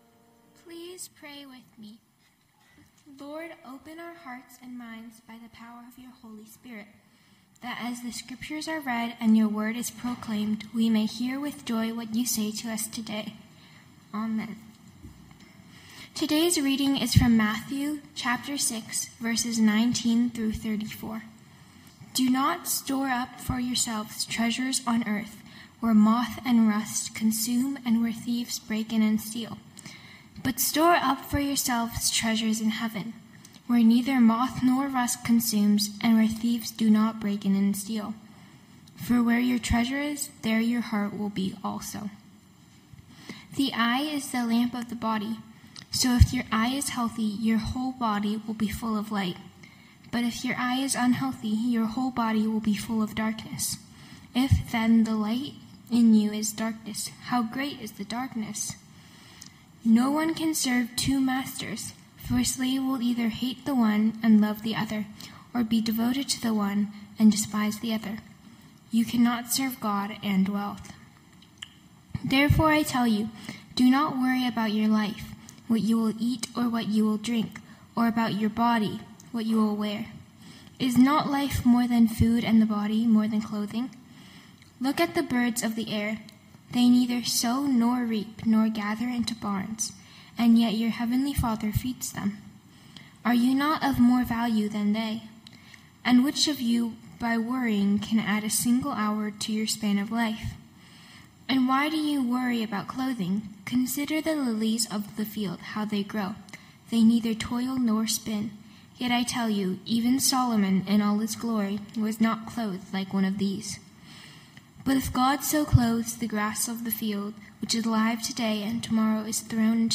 Knox Pasadena Sermons For What It's Worth Mar 15 2026 | 00:25:36 1x 00:00 / 00:25:36 Subscribe Share Spotify RSS Feed Share Link Embed